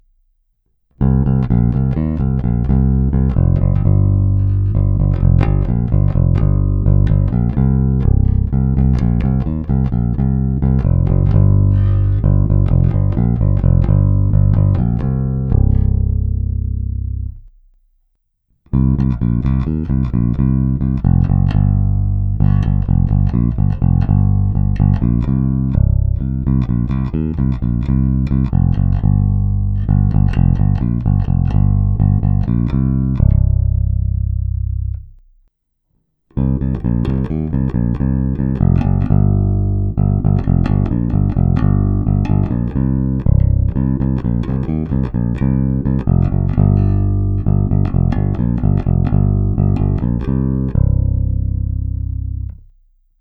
Není-li uvedeno jinak, následující nahrávky jsou provedeny rovnou do zvukové karty a s plně otevřenou tónovou clonou a s korekcemi na nule. Nahrávky jsou jen normalizovány, jinak ponechány bez úprav. Hráno nad použitým snímačem, v případě obou hráno mezi nimi. Na baskytaře jsou nataženy poniklované roundwound pětačtyřicítky Elixir Nanoweb v dobrém stavu.